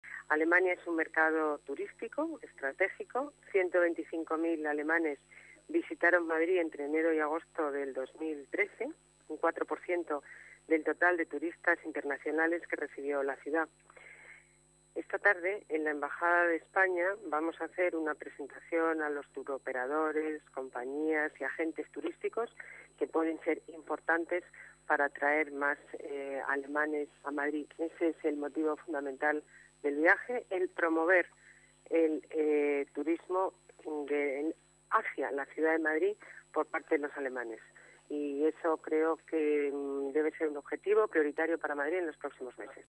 Nueva ventana:Declaraciones de la alcaldesa de Madrid, Ana Botella: Madrid y Berlín, fomento del turismo